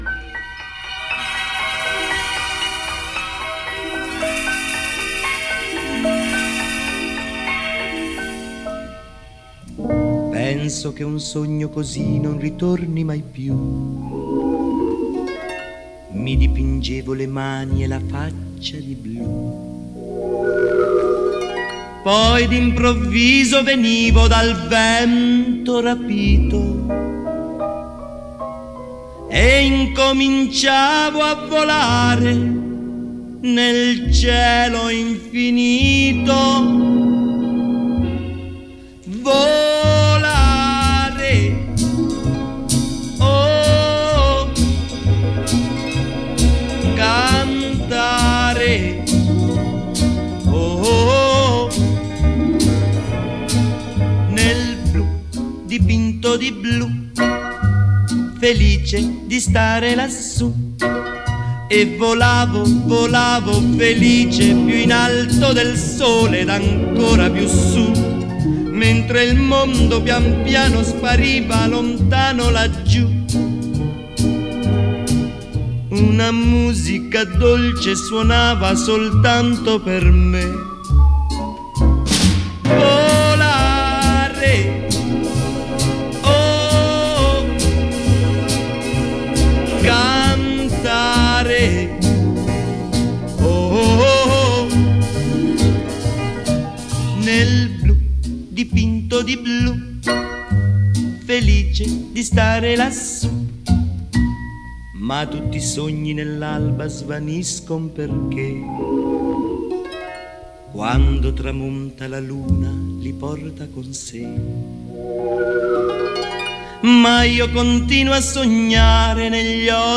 Genre: Pop, Jazz, Soundtrack, Instrumental, Divers, Italian